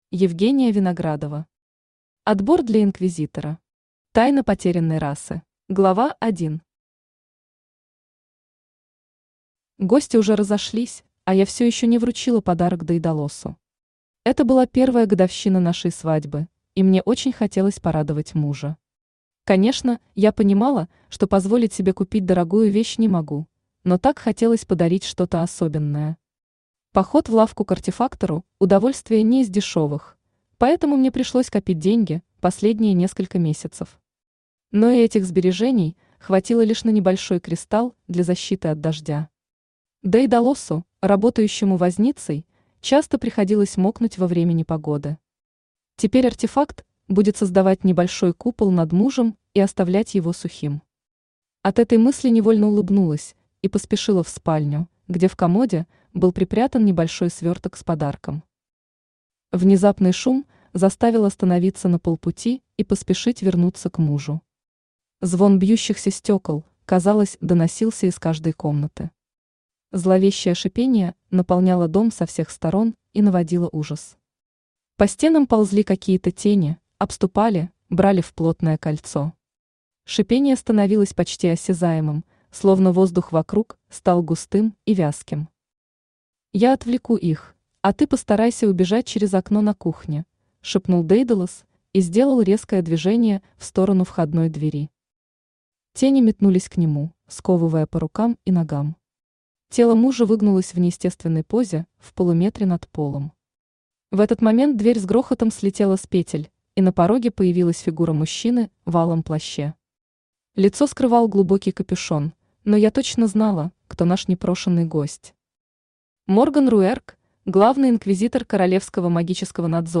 Тайна потерянной расы Автор Евгения Виноградова Читает аудиокнигу Авточтец ЛитРес.